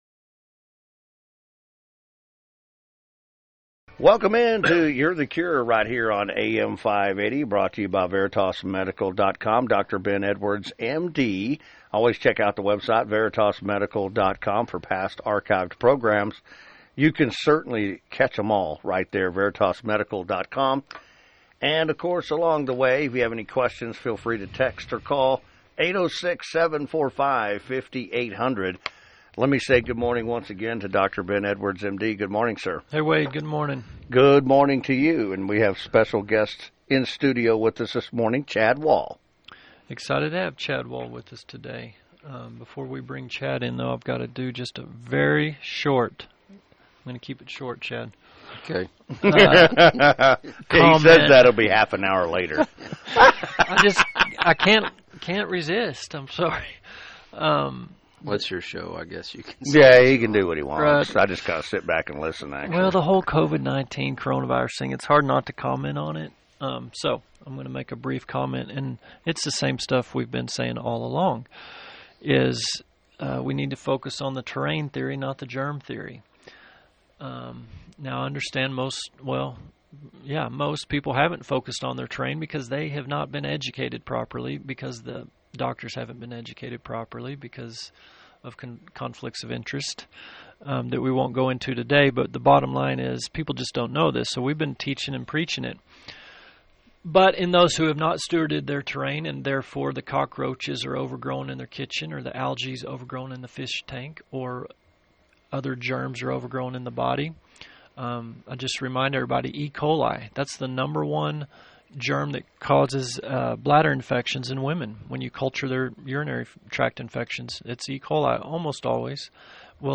a local regenerative farmer.